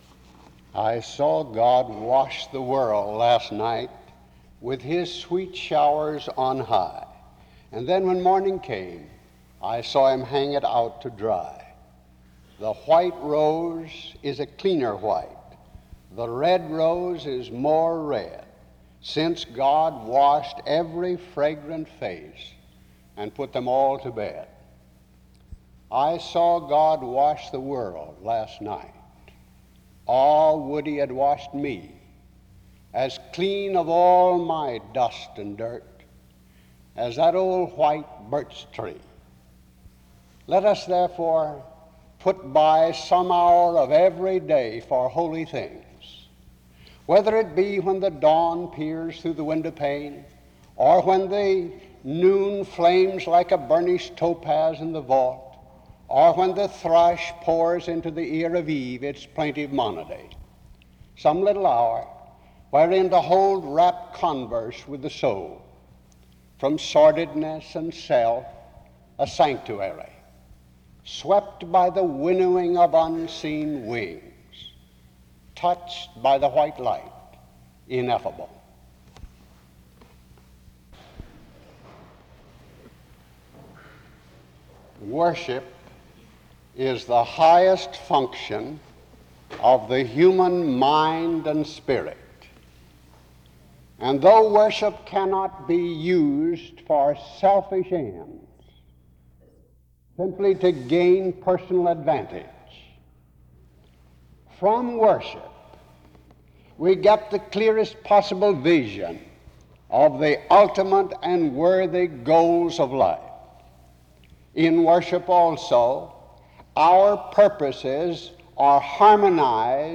SEBTS Chapel and Special Event Recordings SEBTS Chapel and Special Event Recordings